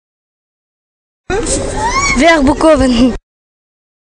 uitspraak Verboekhoven